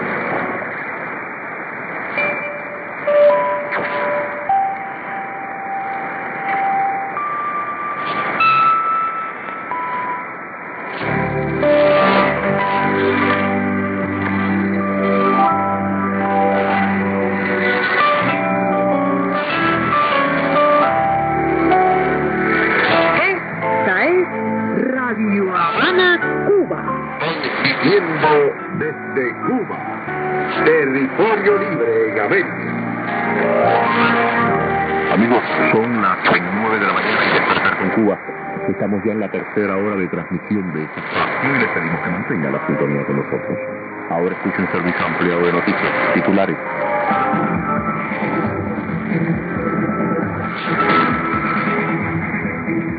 IS: interval signal
ID: identification announcement
ST: signature tune/jingle